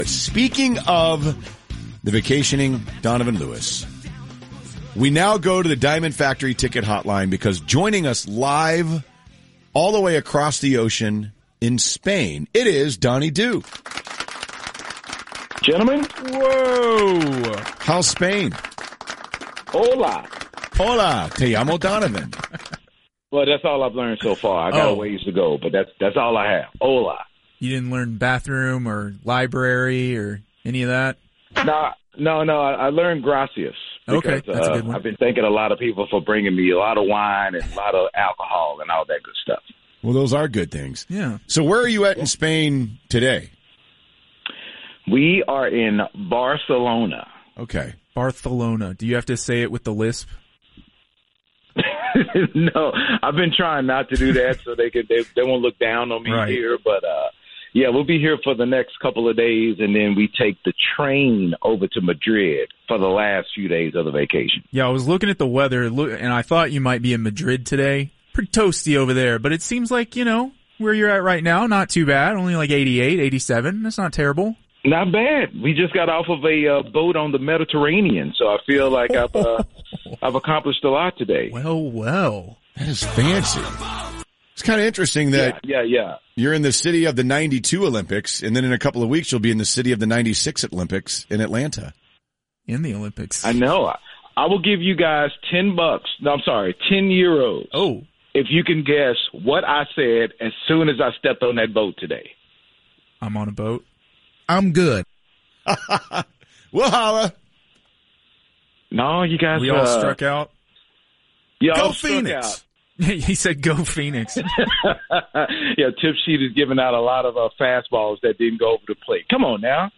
calls in from Spain